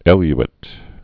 (ĕly-ĭt, -āt)